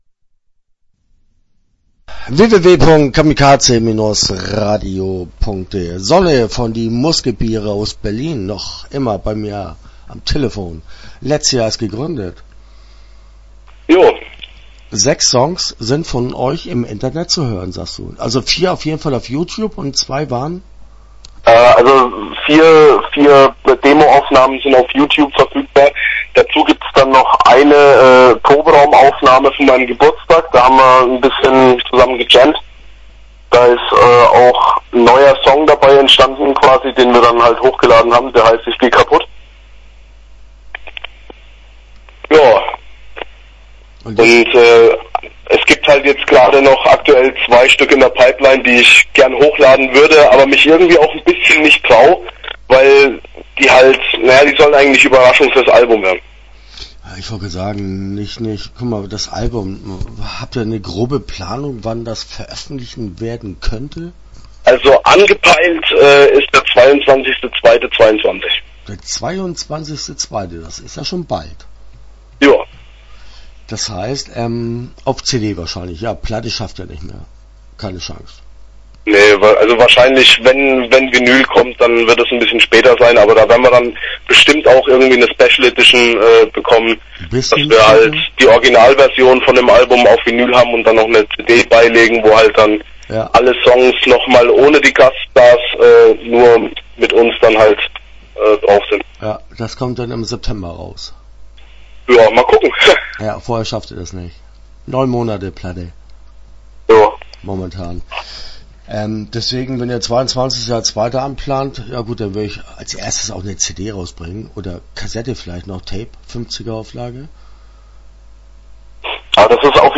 Start » Interviews » Die Muskebiere